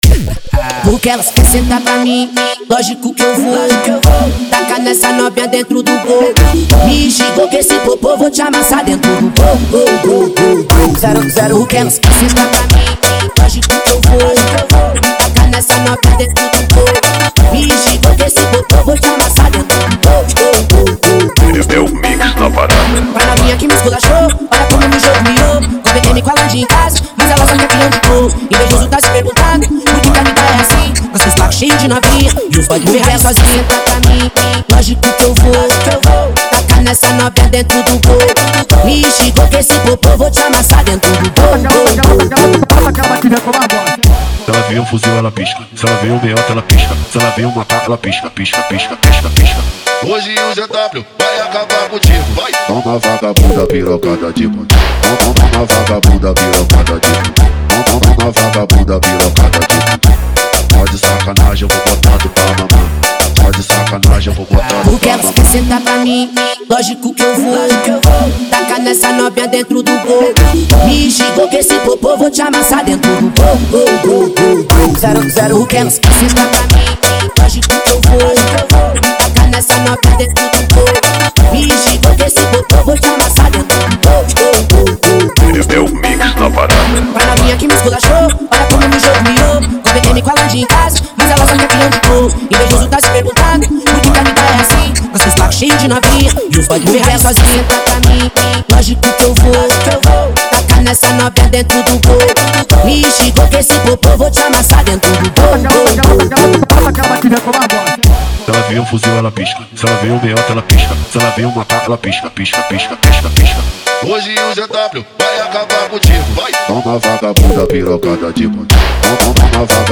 Tecno Melody 2023